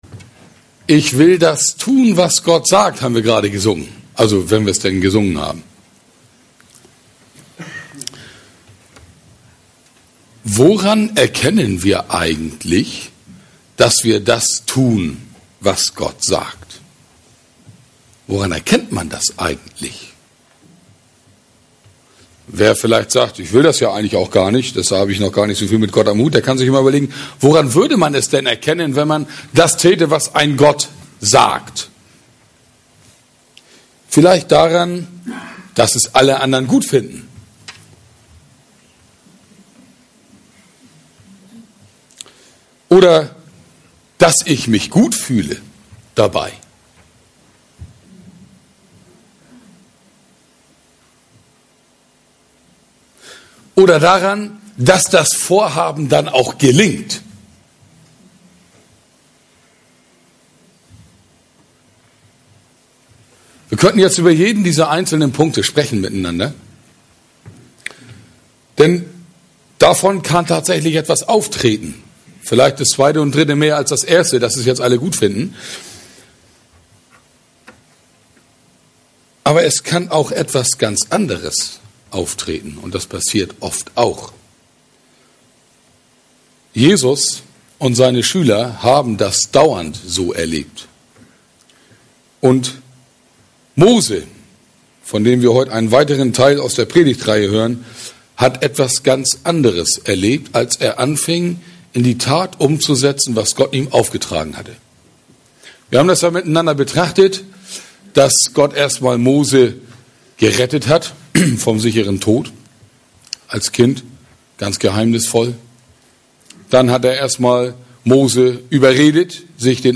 Predigt vom 16. Juli 2017